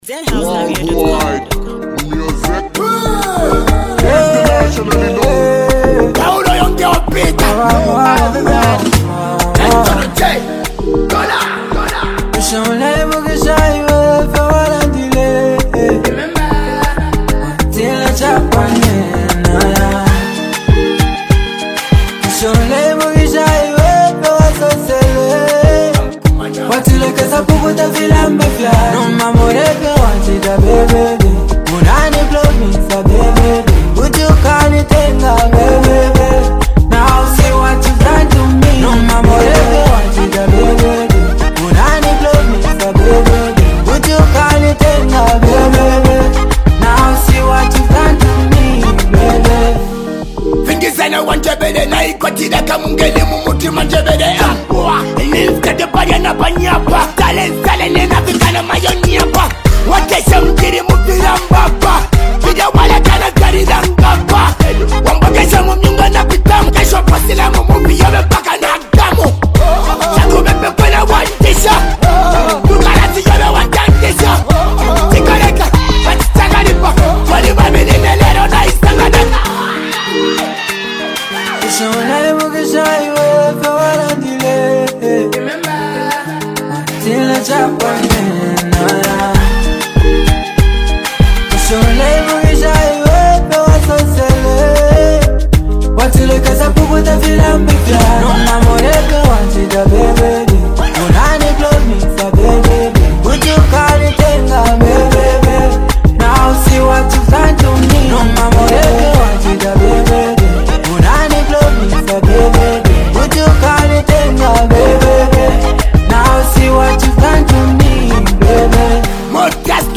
signature street energy mixed with deep lyrical storytelling